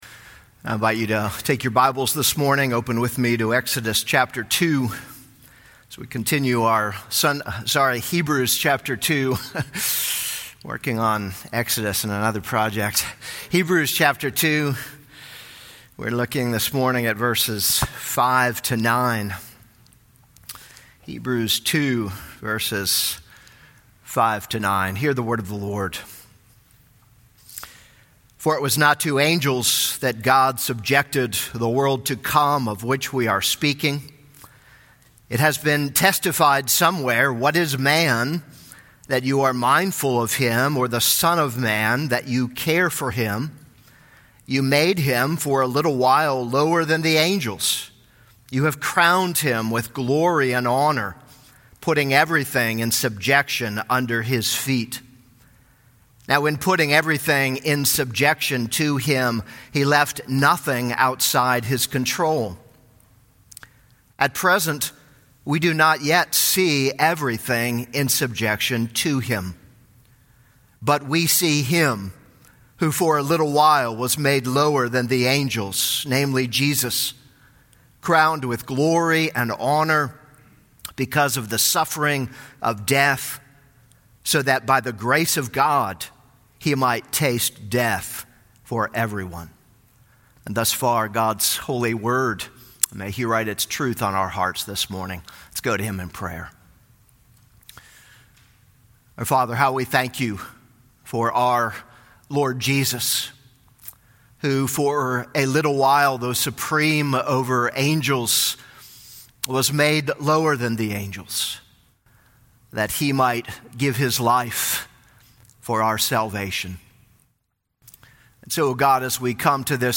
This is a sermon on Hebrews 2:5-9.